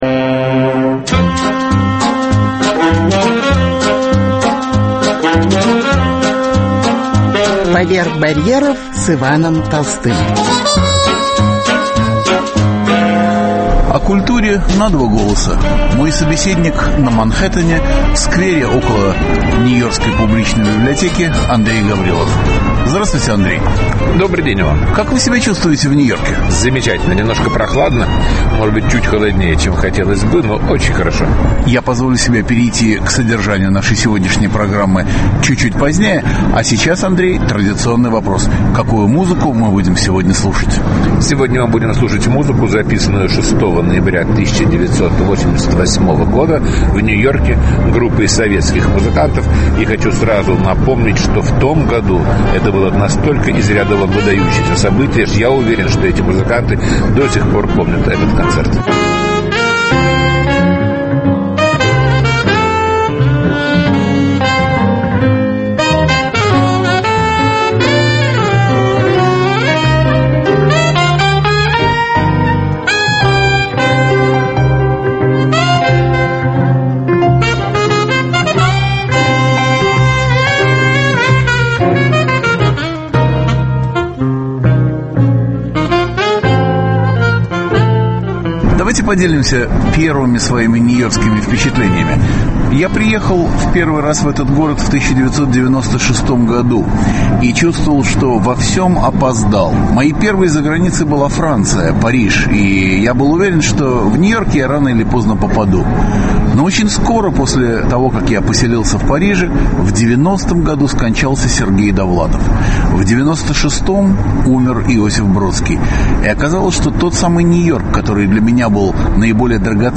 Культурная панорама и редкие музыкальные записи: русский джаз в Нью-Йорке.